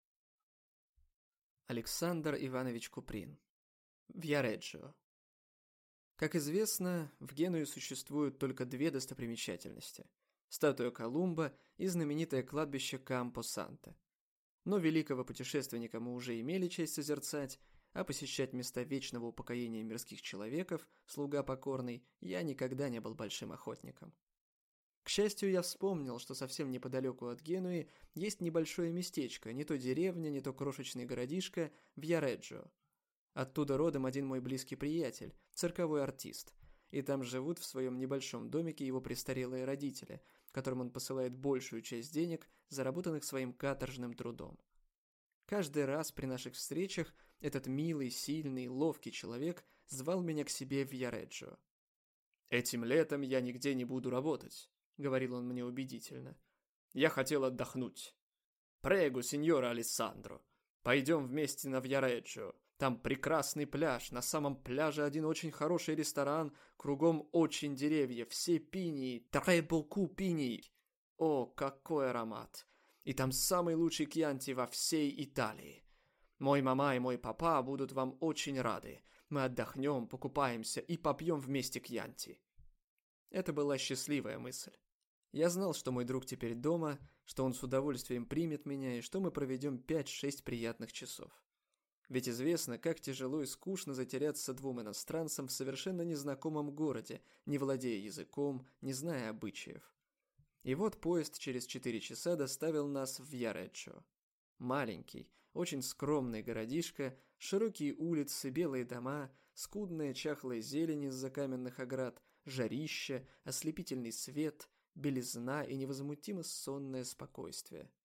Аудиокнига Виареджио | Библиотека аудиокниг